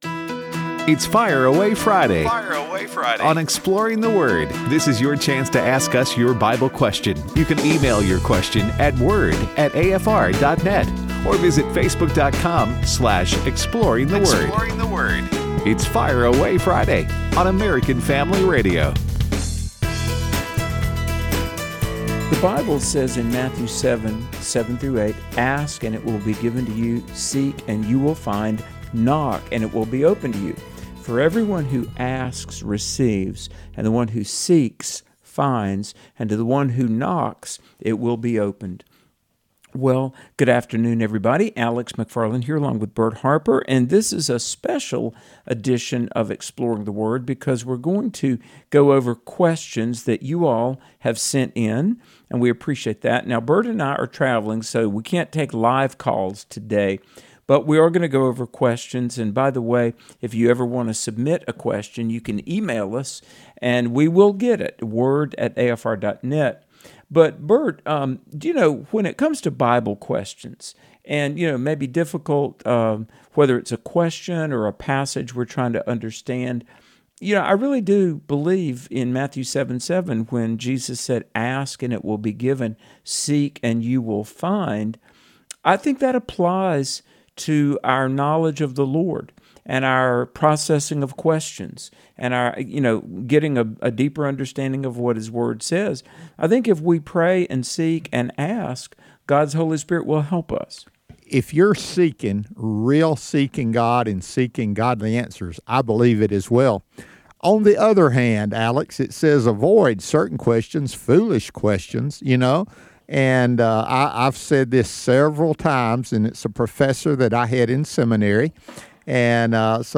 It's Fire Away Friday | prerecorded